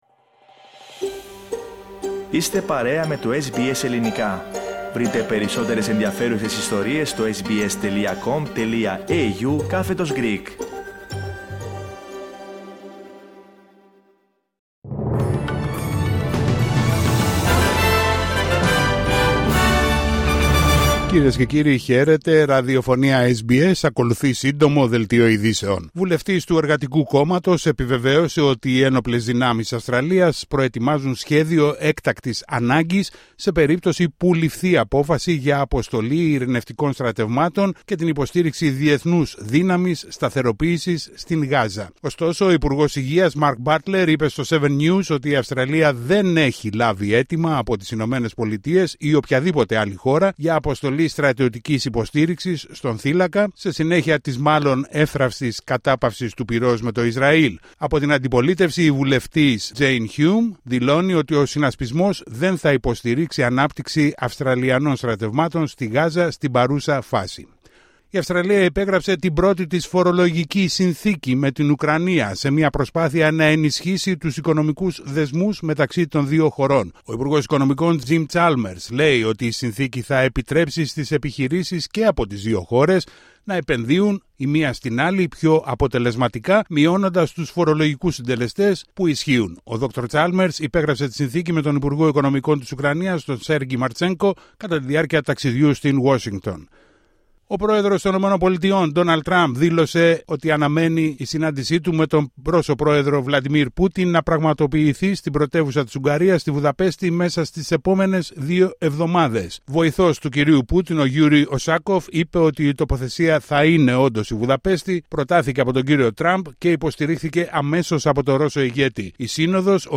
Ειδήσεις στα Ελληνικά από την Αυστραλία την Ελλάδα την Κύπρο και όλο τον κόσμο